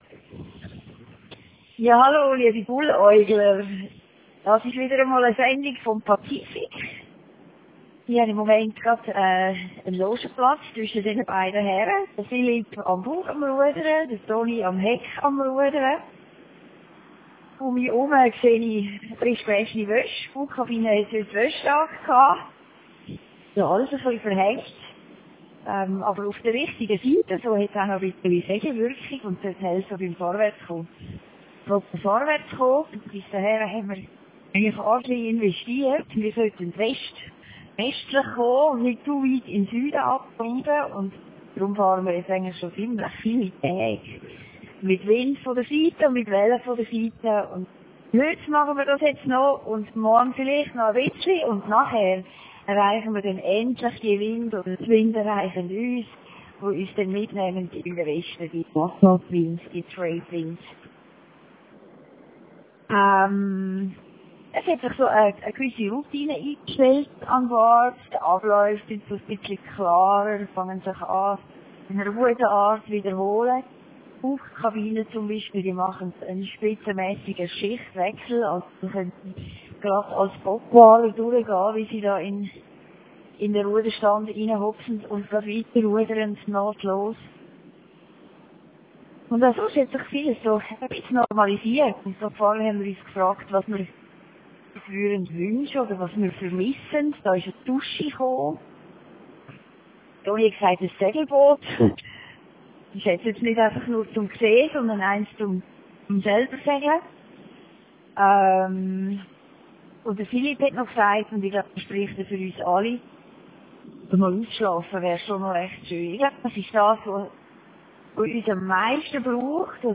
Hier eine Message direkt vom Pazifik.